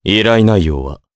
Crow-vox-get_jp.wav